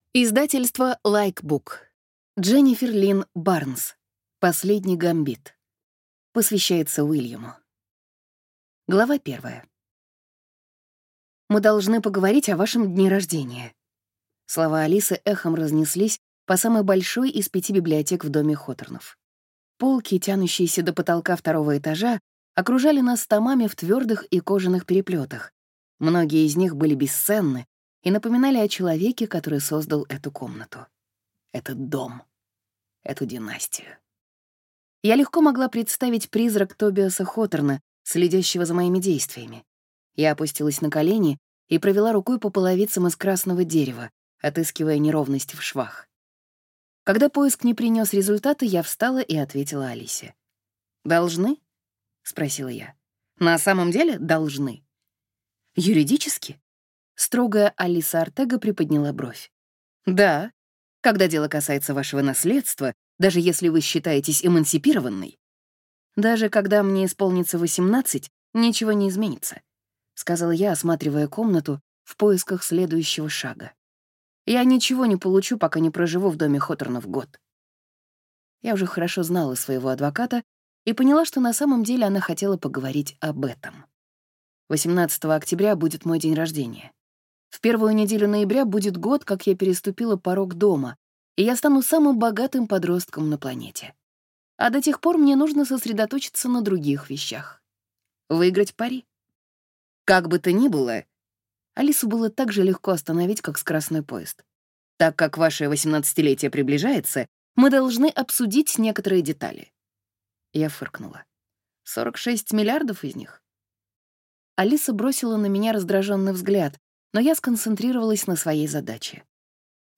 Аудиокнига Последний гамбит | Библиотека аудиокниг